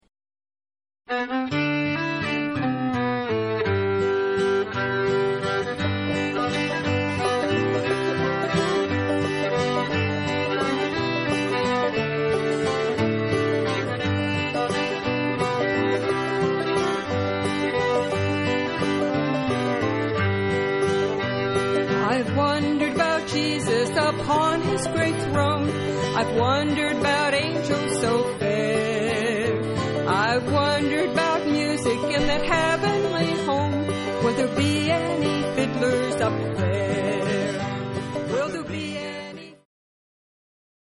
banjo
It sounds like two-finger picking.